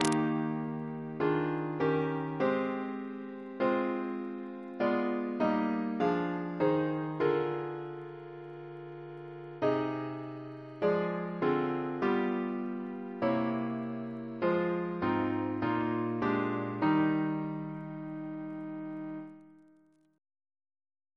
CCP: Chant sampler
Double chant in E♭ Composer: Sir Edward C. Bairstow (1874-1946), Organist of York Minster Reference psalters: RSCM: 14